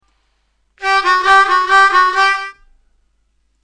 Funkin’ it up on the blues harmonica